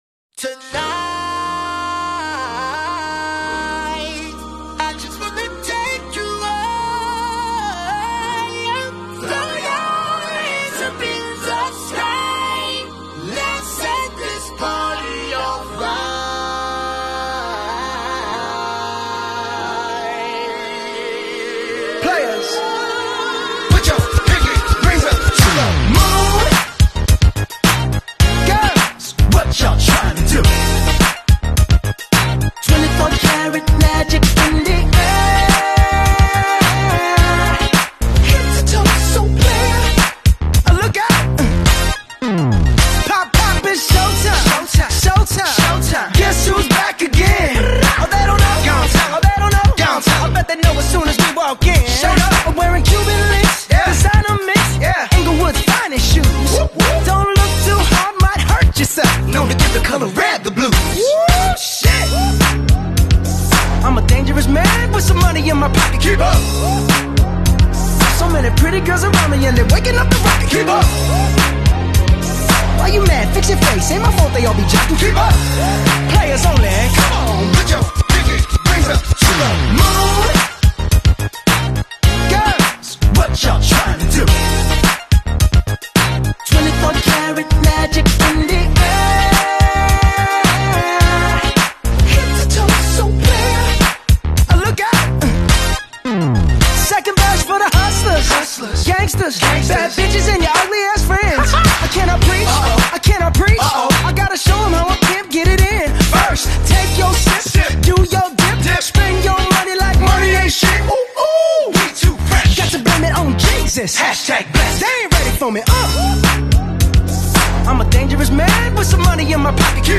Dance music